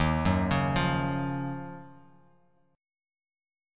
Listen to Ebm9 strummed